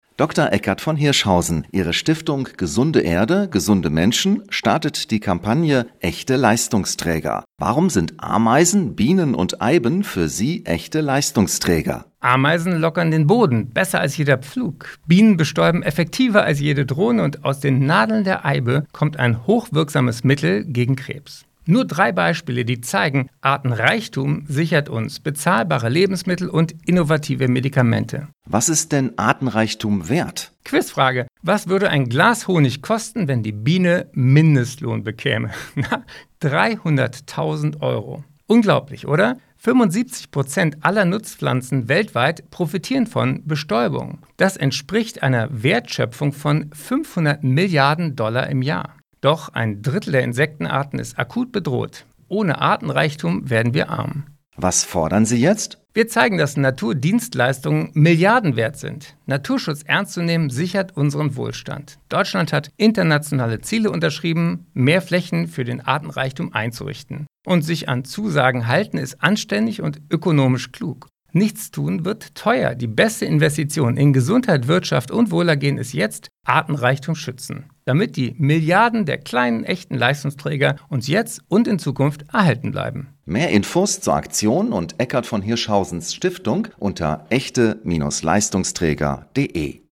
Im Beitrag haben wir mit ihm über seine aktuelle Kampagne „Echte Leistungsträger“ gesprochen. Sie soll deutlich machen, warum Bienen, Ameisen und sogar Singvögel für unsere Gesundheit und unsere Wirtschaft Milliarden wert sind und warum uns ein Verlust von Artenreichtum arm macht.